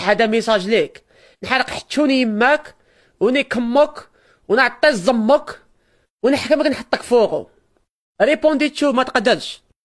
Hoover 2 Sound Effect Download: Instant Soundboard Button